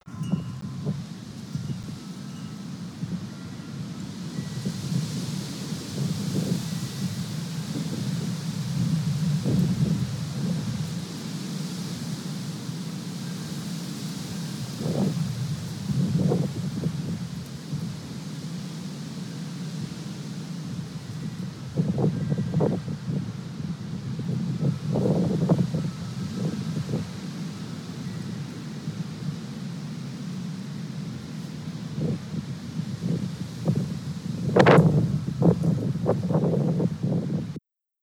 Those wind chimes will be working overtime.